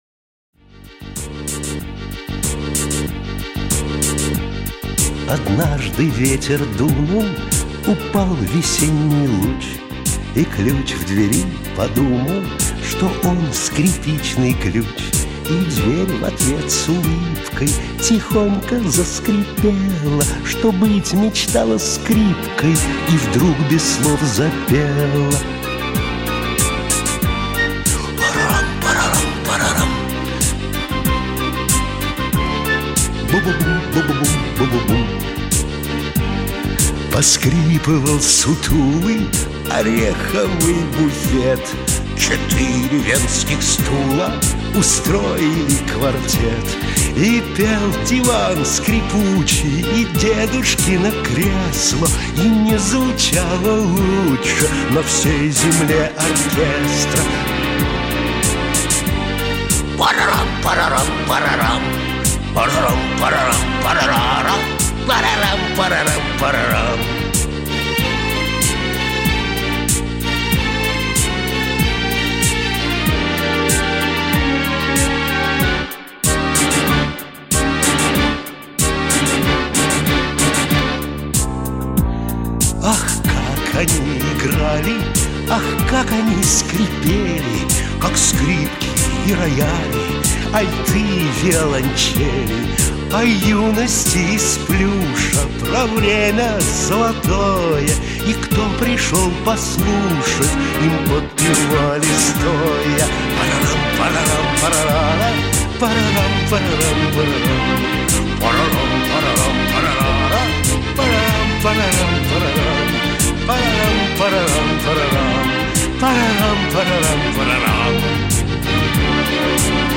• Категория: Детские песни
оркестр